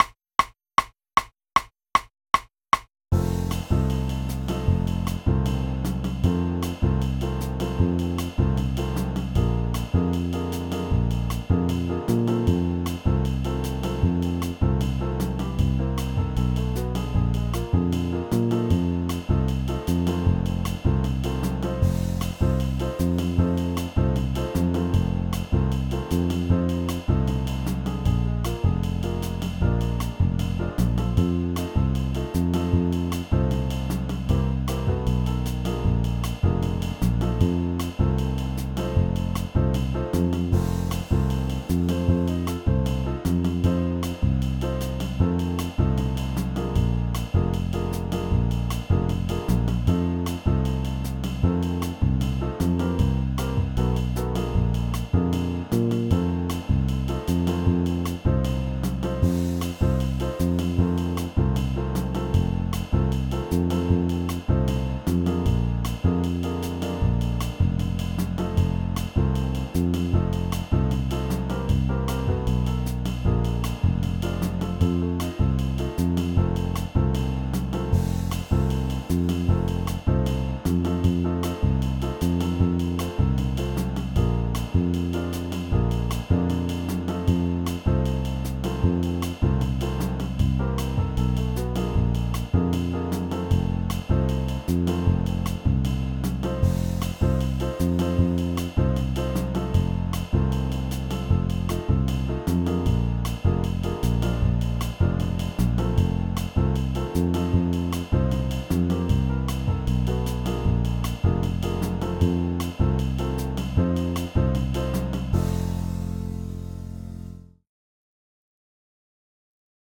You may wish to solo/improvise along with this jam track using only a concert F blues scale OR you can use the chord sheet to show your ability to make the changes, if you are at that level in your understanding/ability.